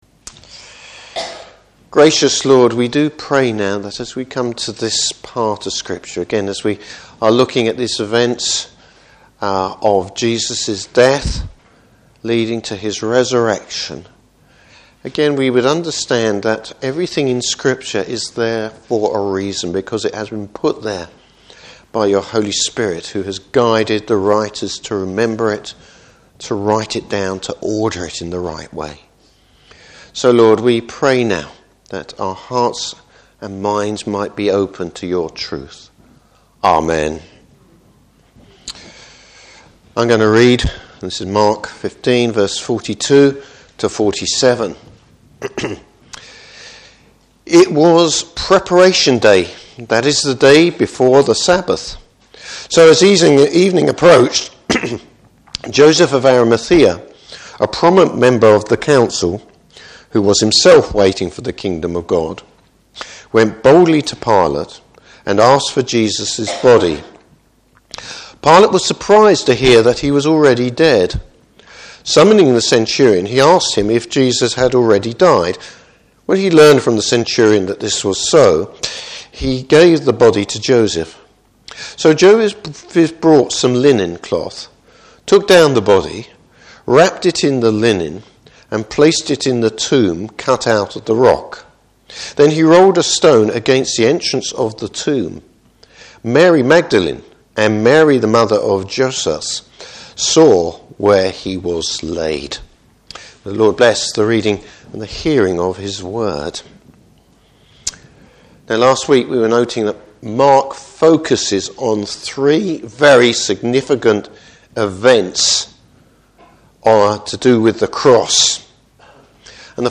Service Type: Morning Service Jesus’ Burial.